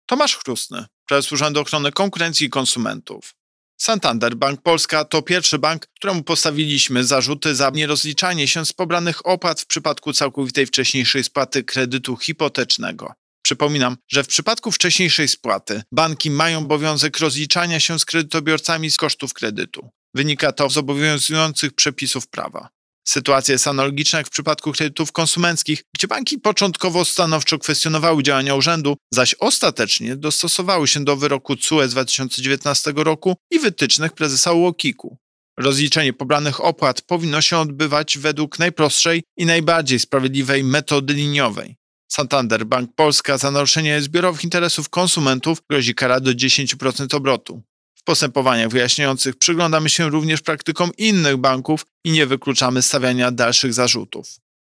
Wypowiedź Prezesa UOKiK Tomasza Chróstnego z 5 października 2022 r..mp3